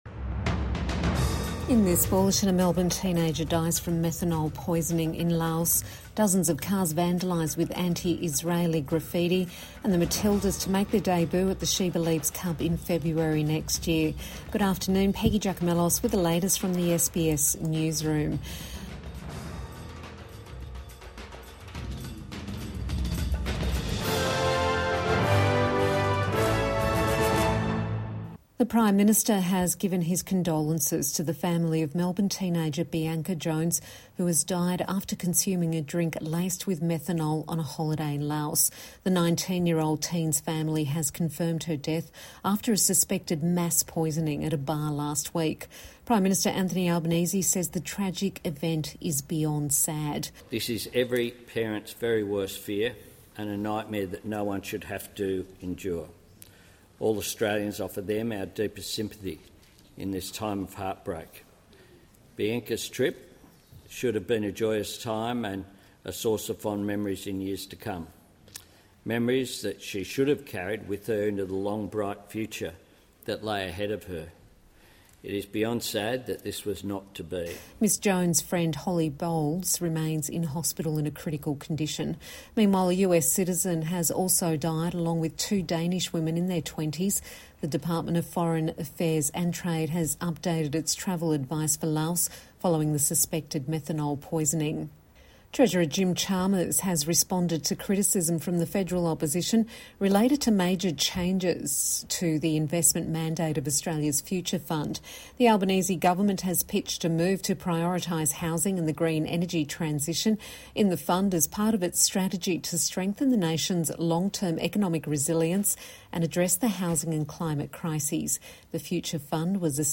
Evening News Bulletin 21 November 2024